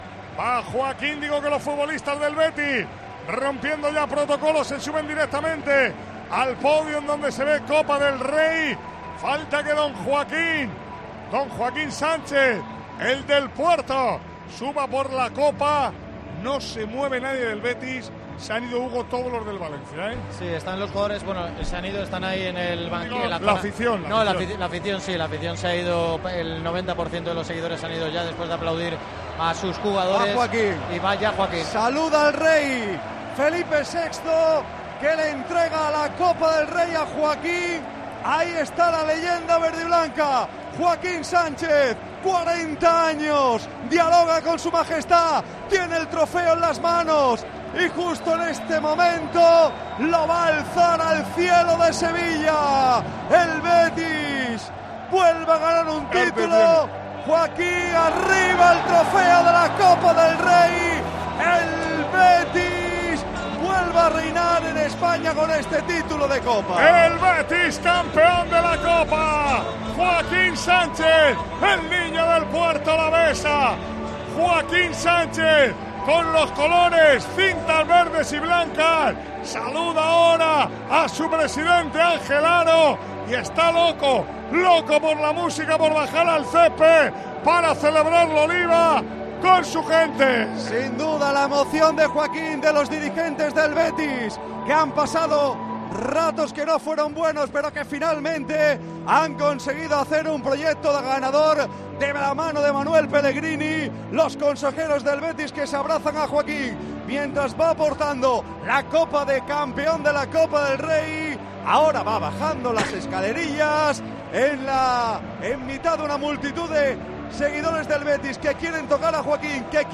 Manolo Lama, Paco González y Juanma Castaño han estado presentes en la entrega del trofeo de campeón al Betis, quien ha levantado su tercera Copa del Rey.